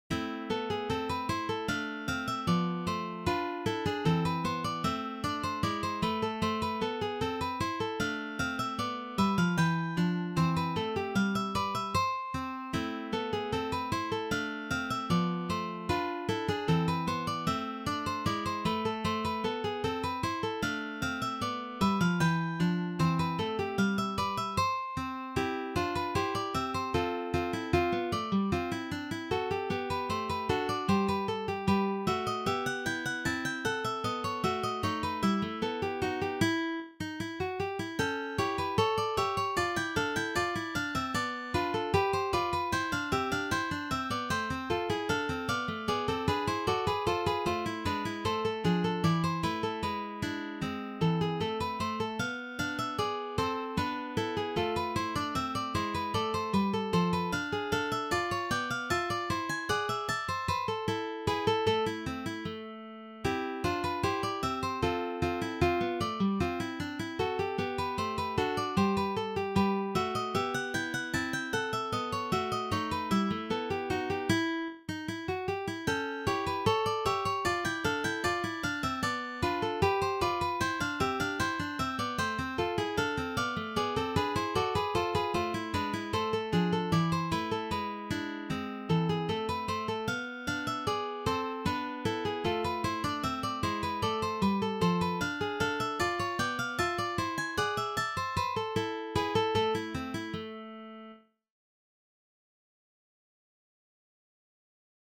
for three guitars
This is from the Baroque period.